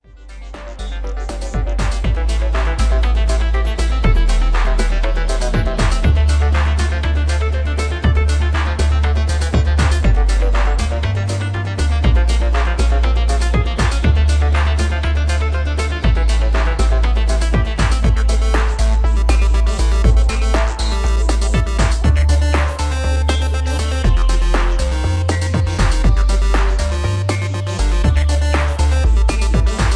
Electro Ambient loop